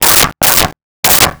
Dog Barking 07
Dog Barking 07.wav